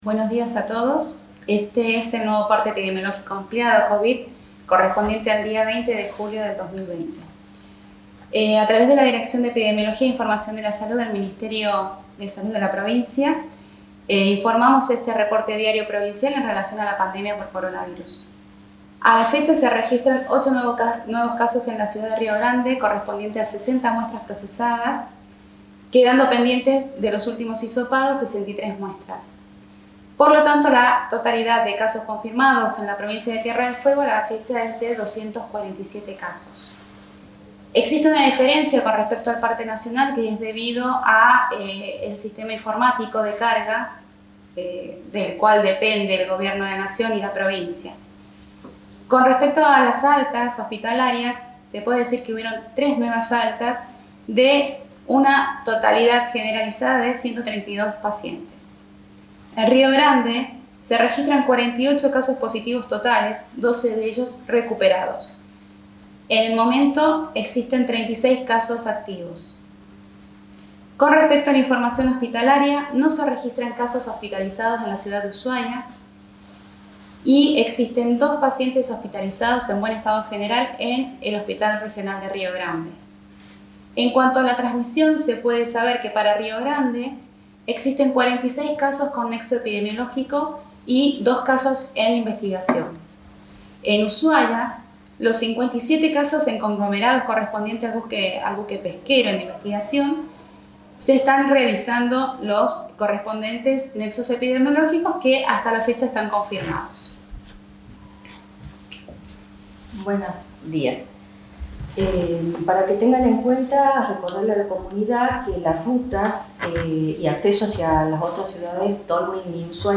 realizaron un nuevo parte extendido explicando la situación en la que se encuentra la provincia en relación a la pandemia de Covid-19.